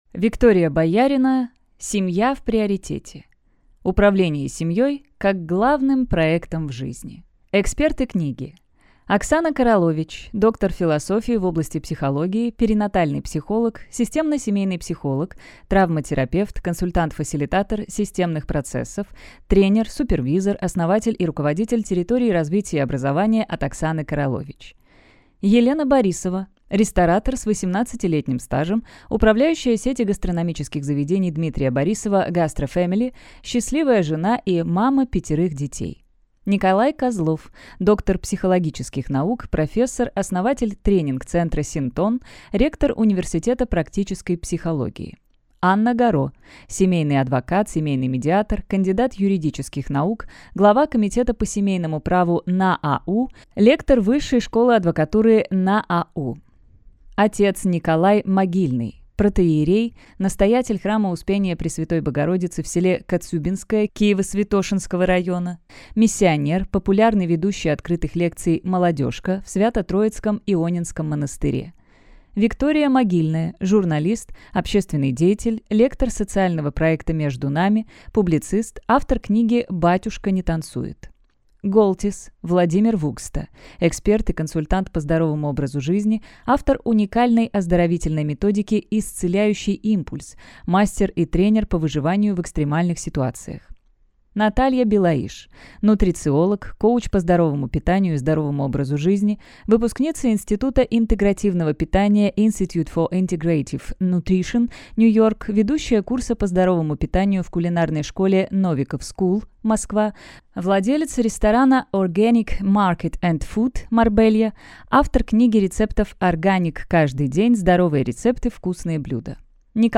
Аудиокнига Семья в приоритете. Управление семьей как главным проектом в жизни | Библиотека аудиокниг
Прослушать и бесплатно скачать фрагмент аудиокниги